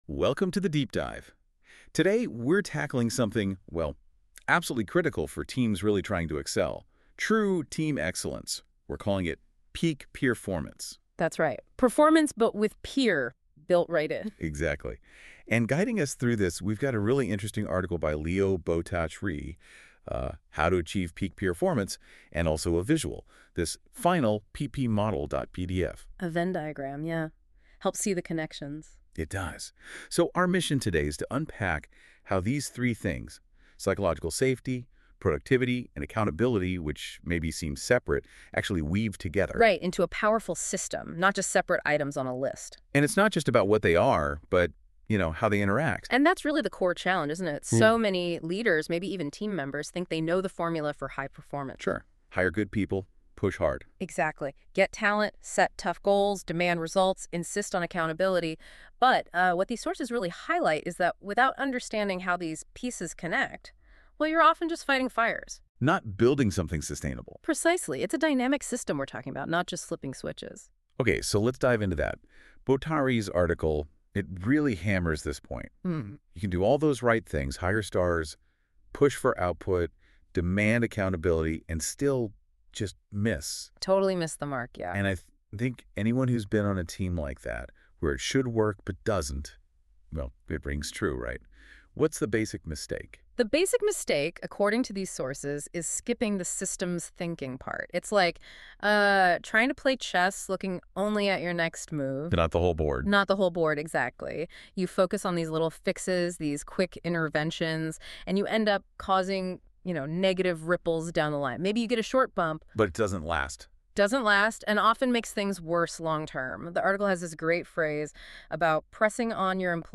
If there is an asterisk (*) next to the title of one of the CEOWORLD Magazine articles listed below, that means you'll also find a link to a Peernovation Deep Dive, powered by Google's NotebookLM.